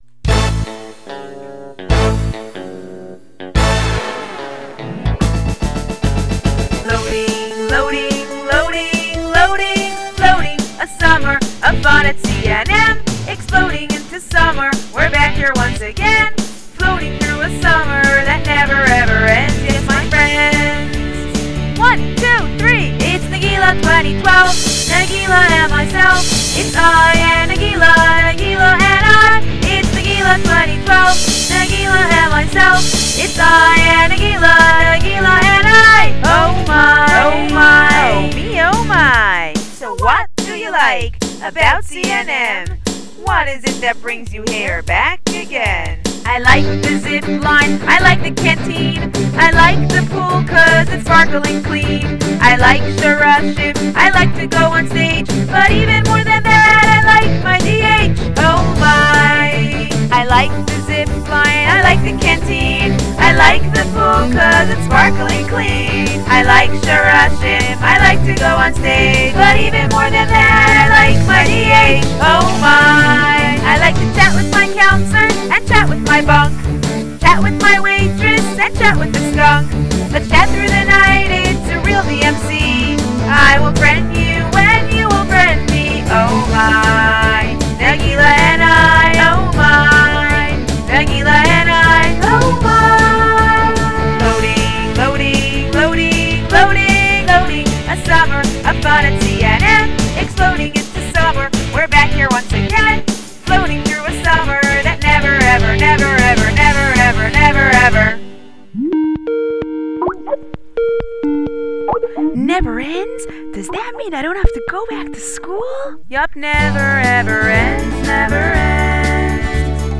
2012 ICNM (Vocals By CNM Girls)
2012- iCNM. Vocals by CNM Girls.m4a